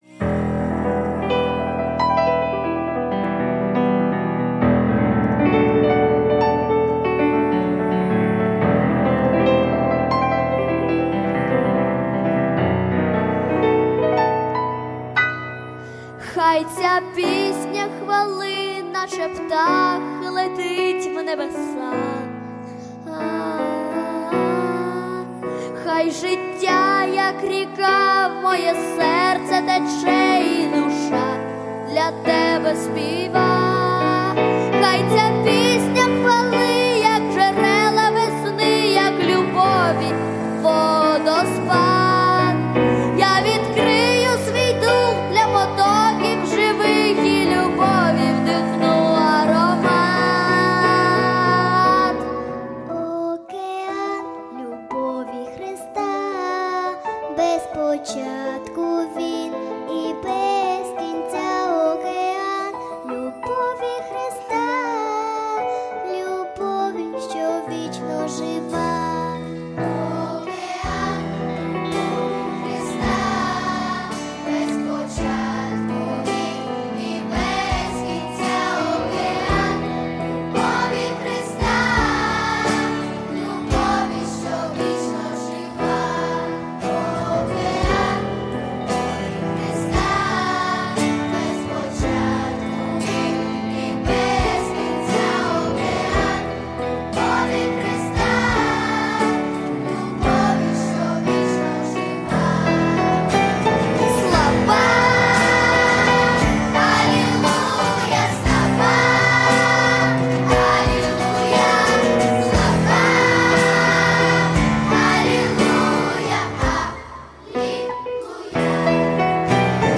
• Качество: Хорошее
• Жанр: Детские песни
христианские песни